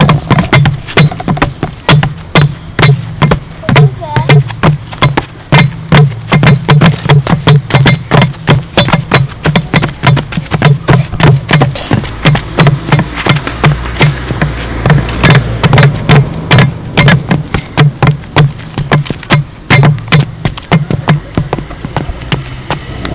Nel primo posto auto si trova questo suono!
6 anni Indizi dei bambini È un rumore coraggioso!
Fa un rumore botola e per farlo suonare dobbiamo saltare su una cosa che va nell’acquedotto!